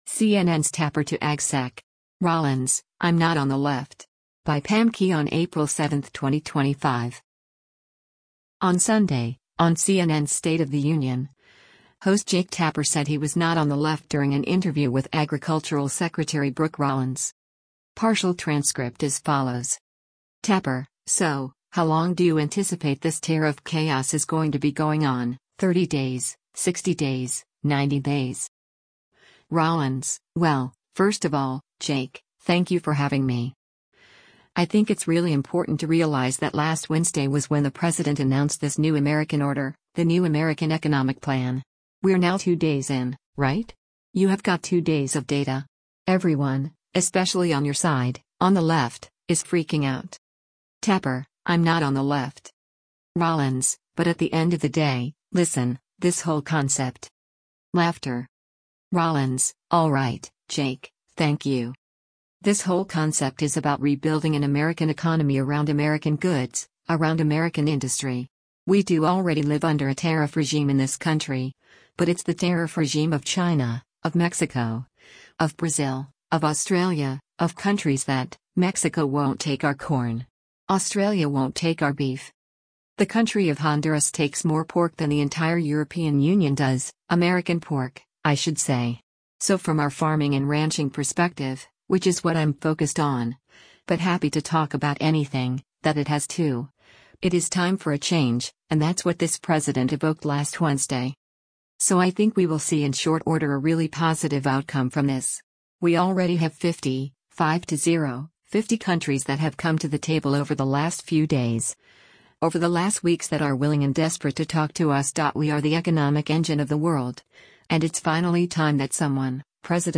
On Sunday, on CNN’s “State of the Union,” host Jake Tapper said he was “not on the left” during an interview with Agricultural Secretary Brooke Rollins.